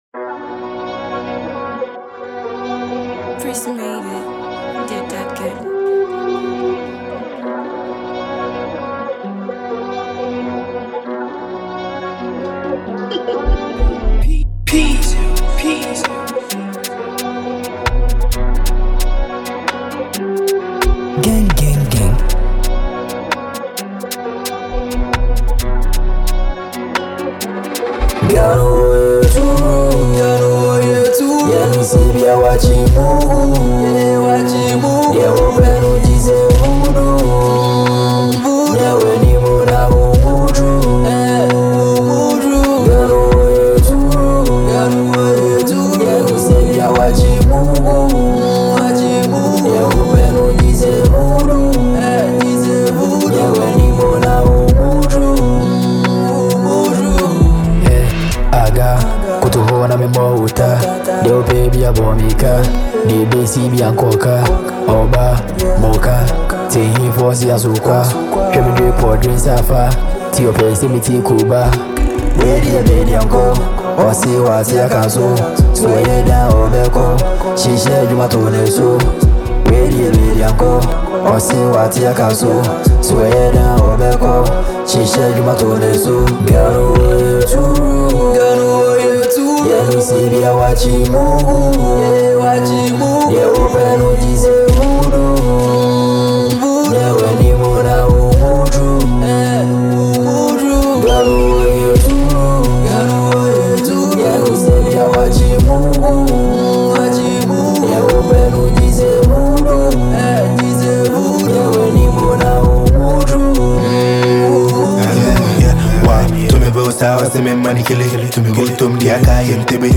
a Ghanaian asakaa rapper